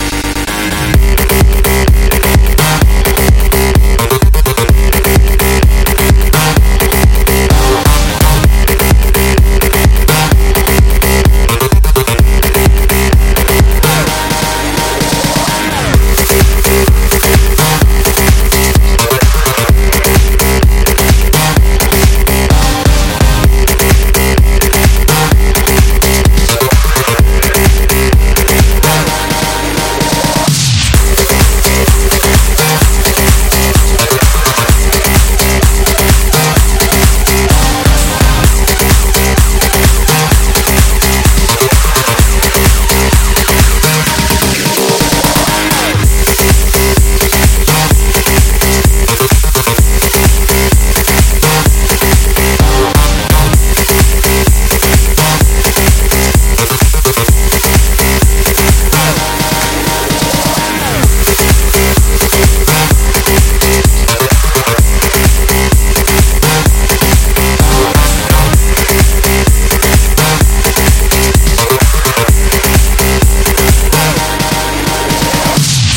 • Качество: 138, Stereo
progressive house
electro house